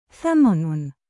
音标: /ˈθaman/